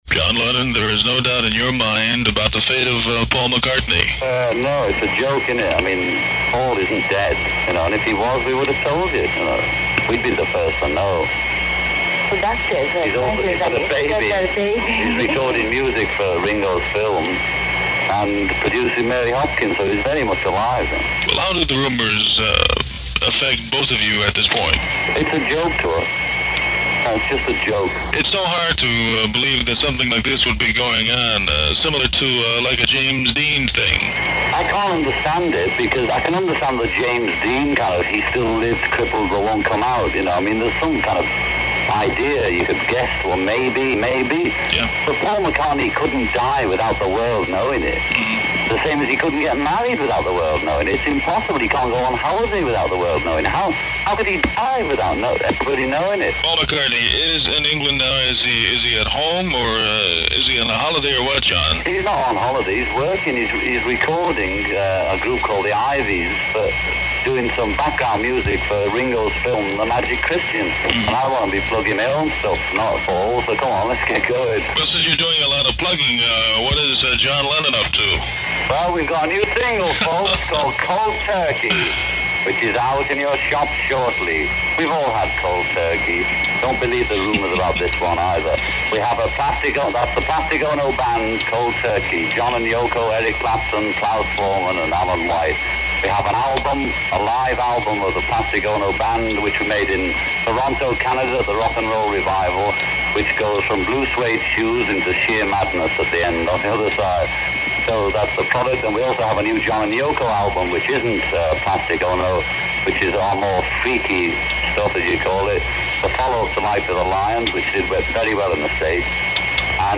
Lennon’s tone was clear and candid.
Even Yoko Ono, present during the call, chimed in to dismiss the hysteria.
This aircheck is a cultural artifact: a moment when radio didn’t just spread a legend—it challenged it.
Audio Digitally Remastered by USA Radio Museum